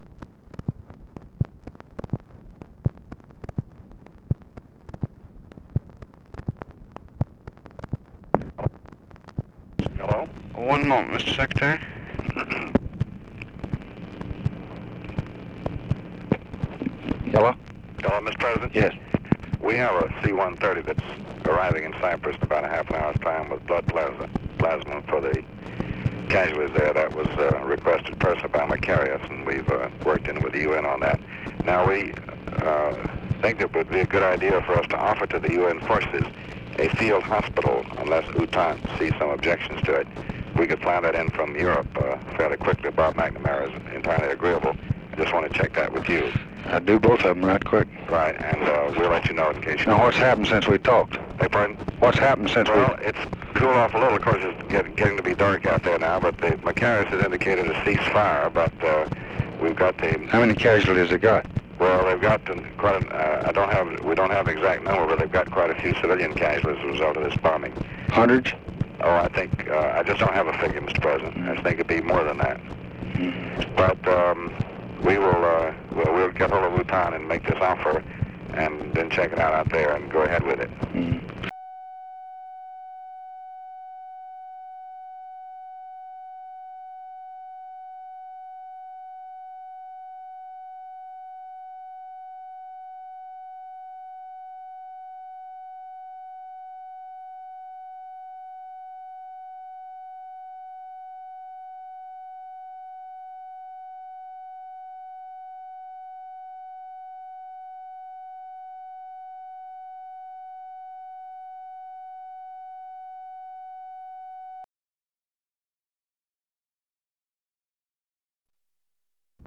Conversation with DEAN RUSK, August 9, 1964
Secret White House Tapes